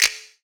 MZ Snap [Juicy].wav